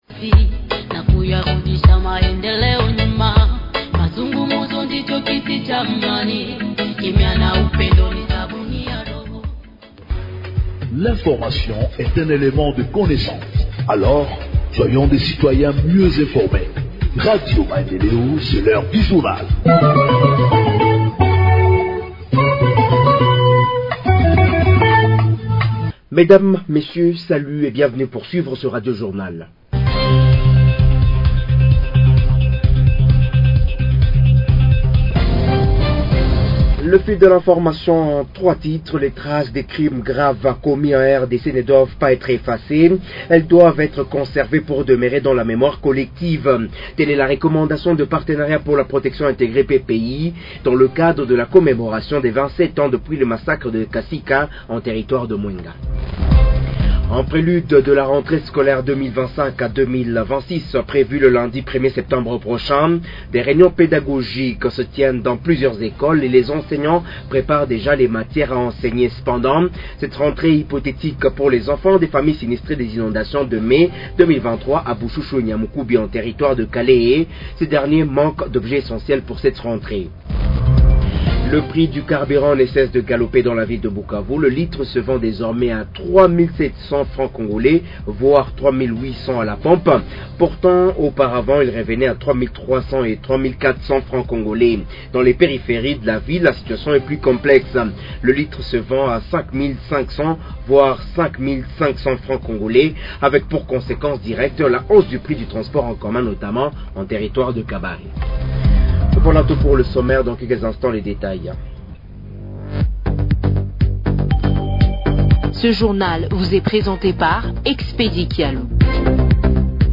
Journal en Français du 27 Août 2025 – Radio Maendeleo